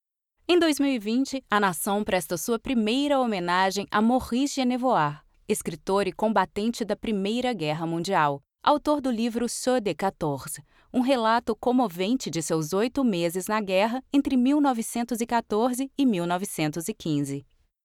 Kommerziell, Junge, Sanft
Audioguide